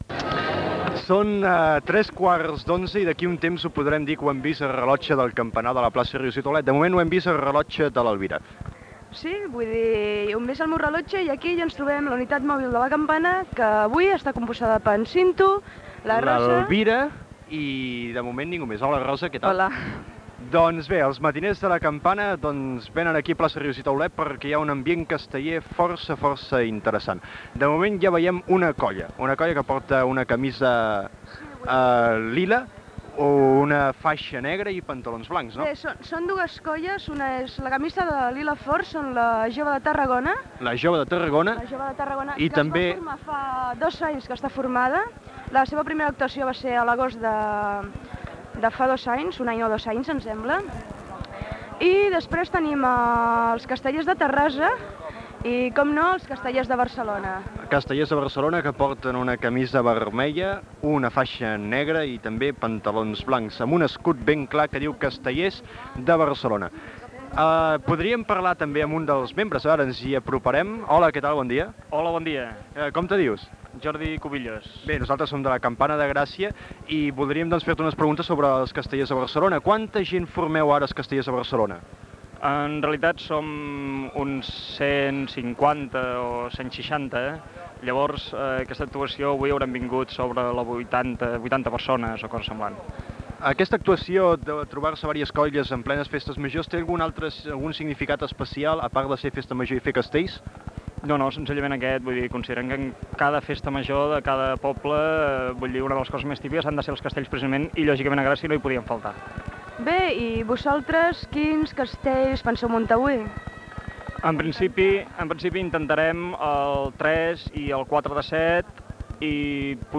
Jornada castellera a la vila de Gràcia. Entrevista als Castellers de Barcelona, els Xiquets de Tarragona, els Grallers de Gràcia i els Grallers de Terrassa.
Informatiu